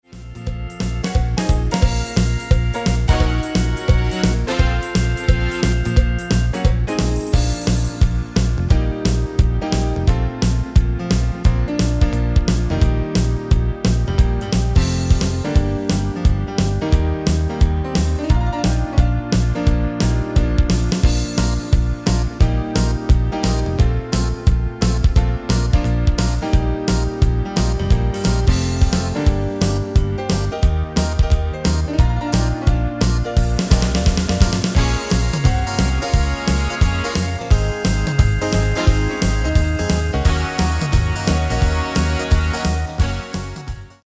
Demo/Koop midifile
Genre: Musical / TV / Film
- GM = General Midi level 1
- Géén vocal harmony tracks
Demo's zijn eigen opnames van onze digitale arrangementen.